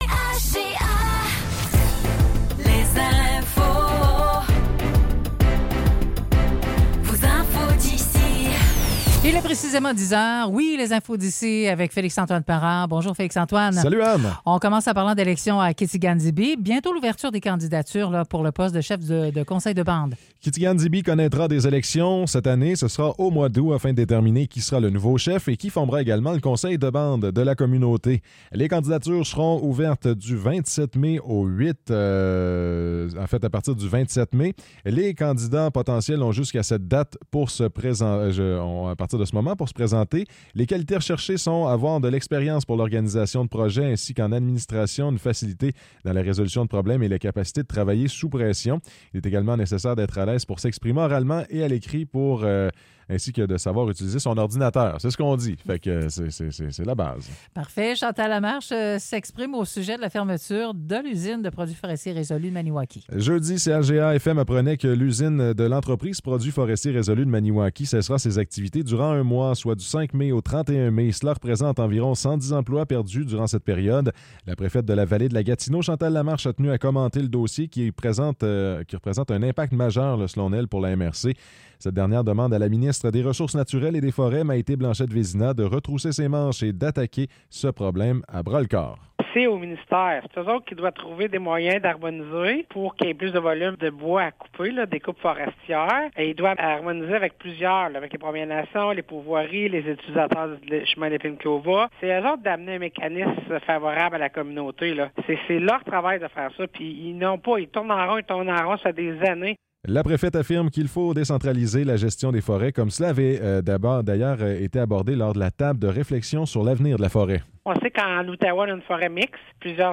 Nouvelles locales - 29 avril 2024 - 10 h